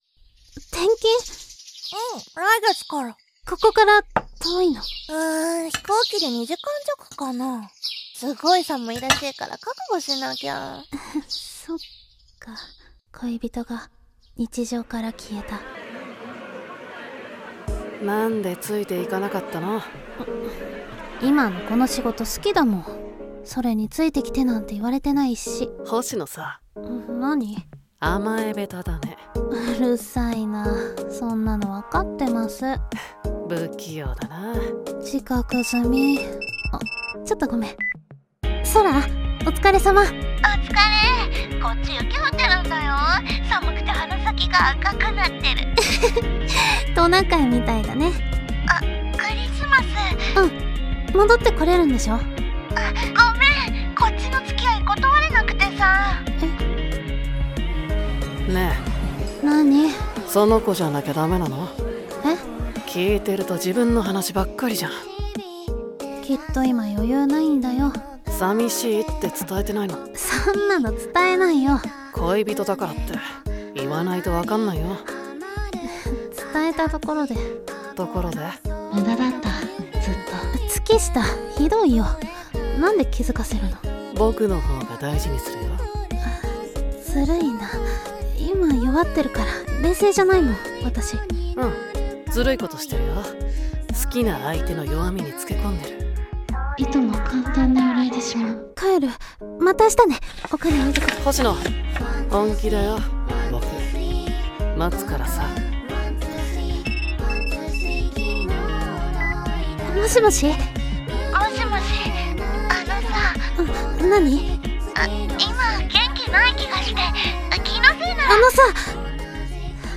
【3人声劇】